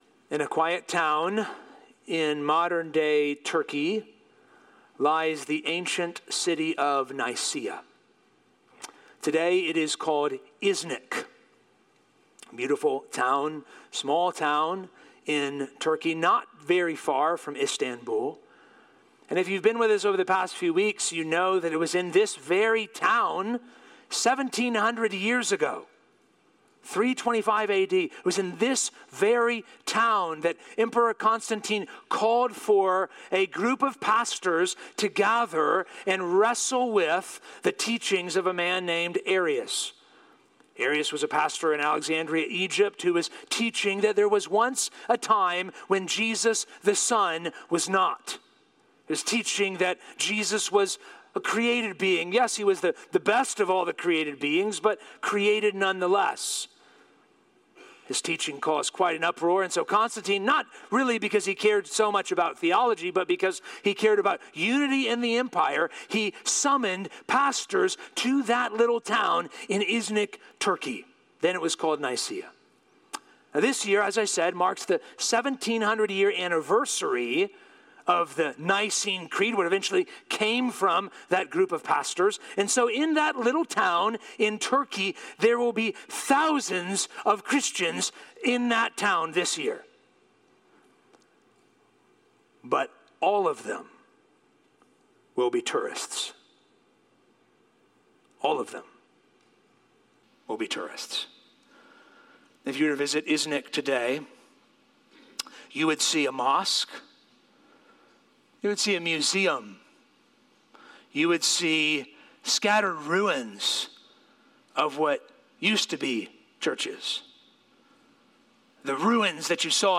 This Week's Sermon